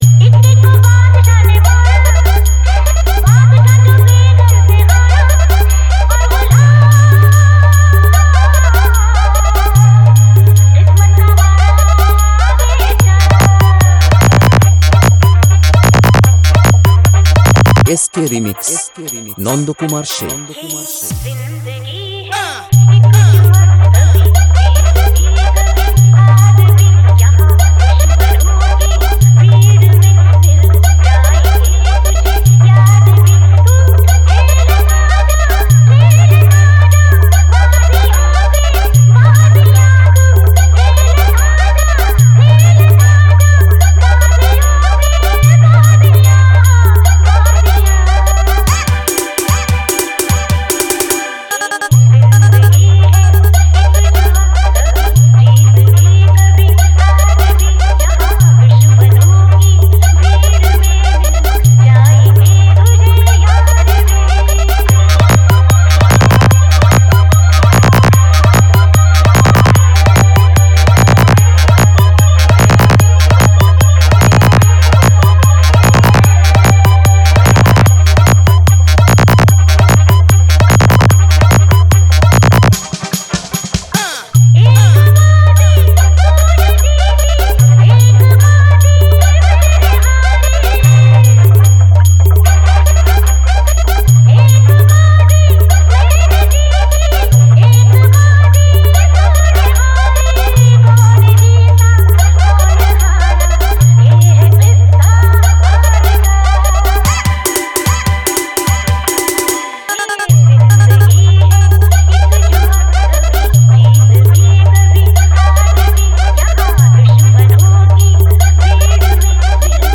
New Styel Long Range Setting Humbing Mix